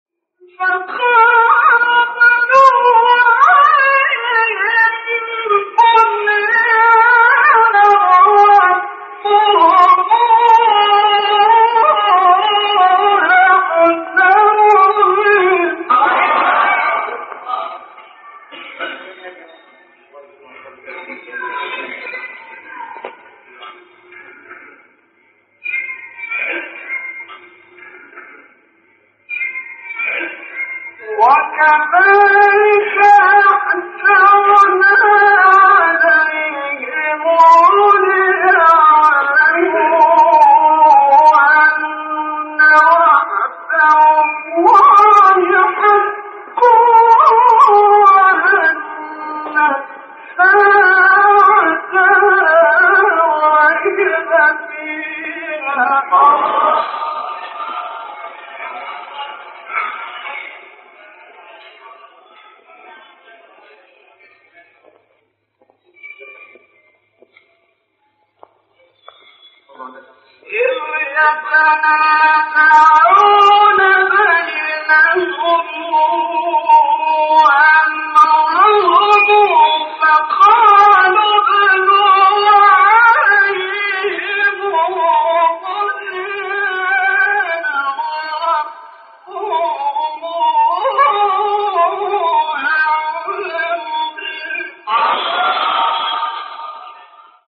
آیه 21 سوره کهف استاد محمد رفعت | نغمات قرآن | دانلود تلاوت قرآن